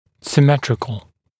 [sɪ’metrɪkl][си’мэтрикл]симметричный